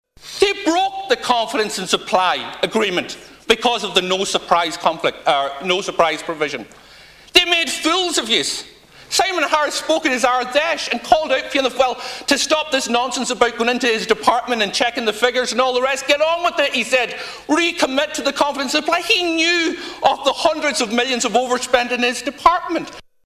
Donegal Deputy Pearse Doherty hit out at Fianna Fáil for their decision to abstain despite saying they have no confidence in the Minister: